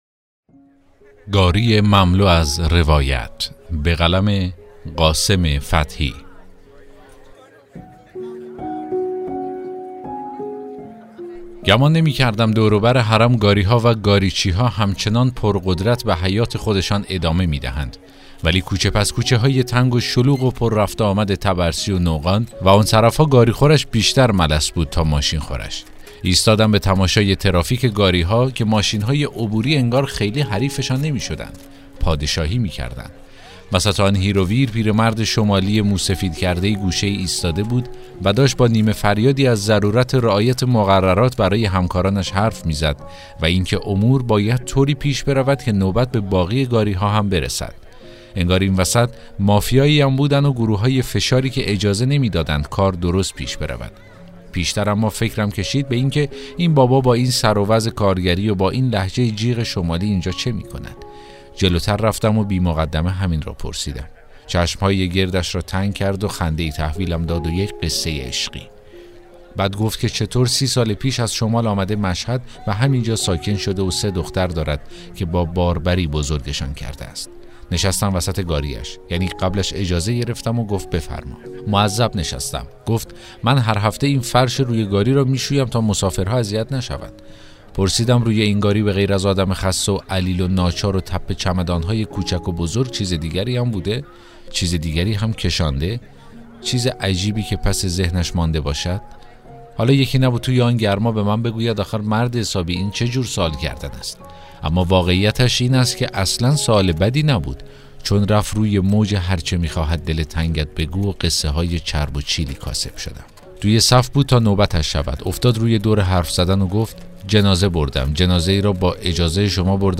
داستان صوتی: گاری مملو از روایت